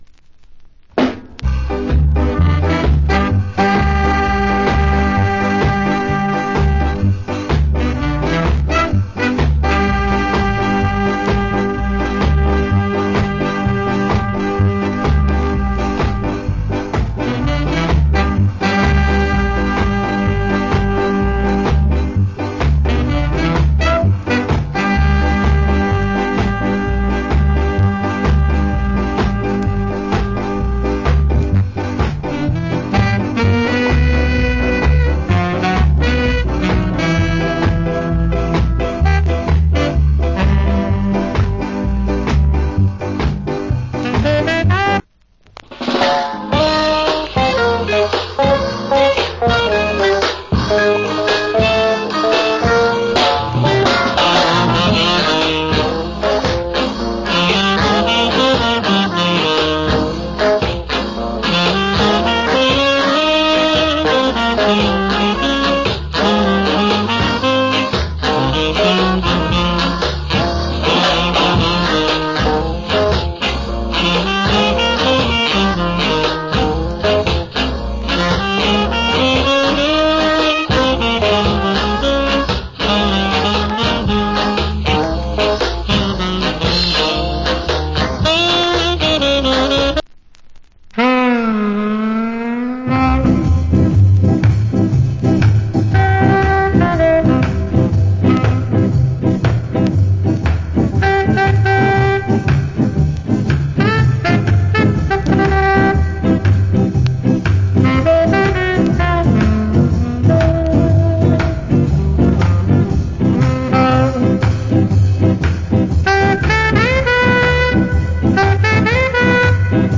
Nice Ska Inst. 80's Press.